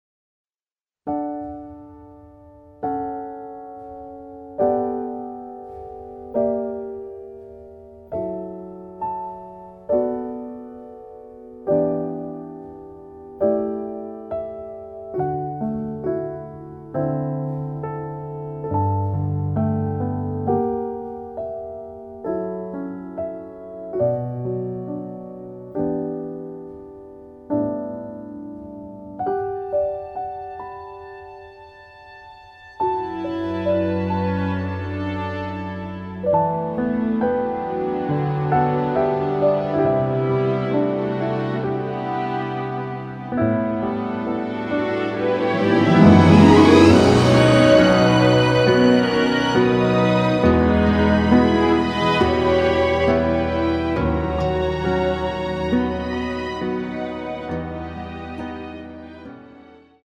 [공식 음원 MR]
키 D 가수